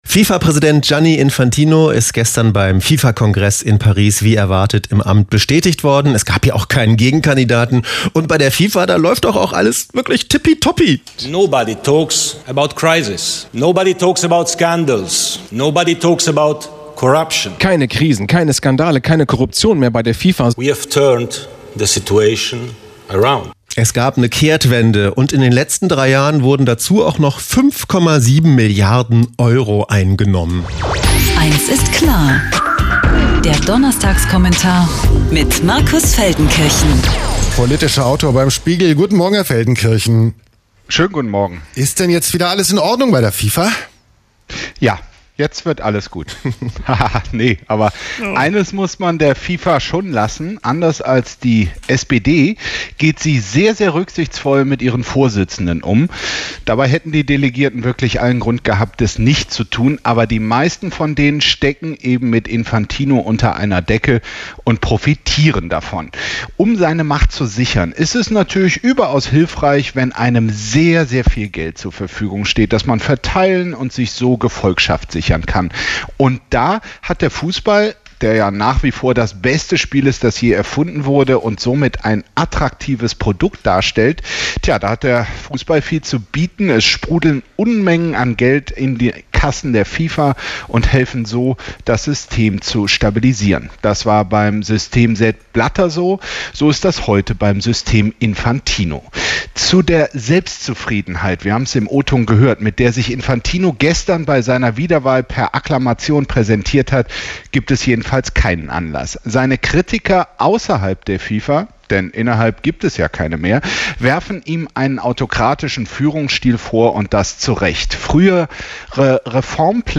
Kommentar